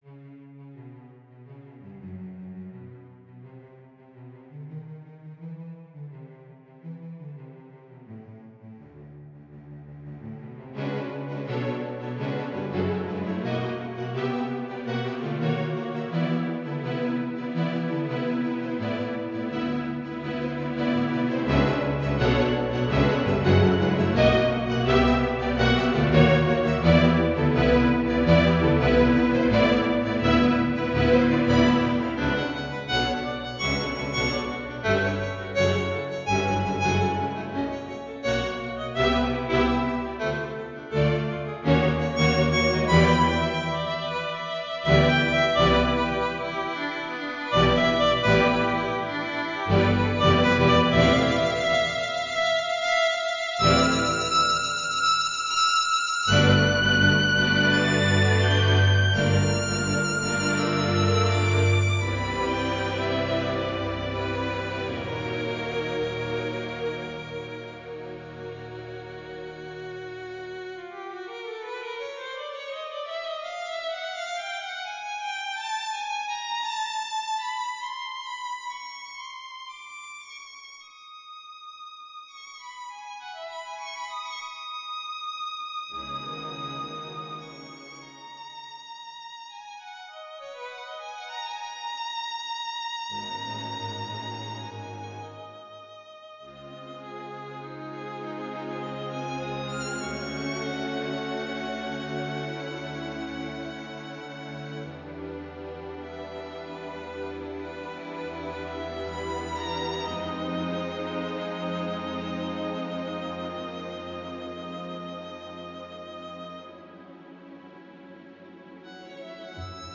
A NARRATIVE CONCERTO FOR VIOLIN AND STRING ORCHESTRA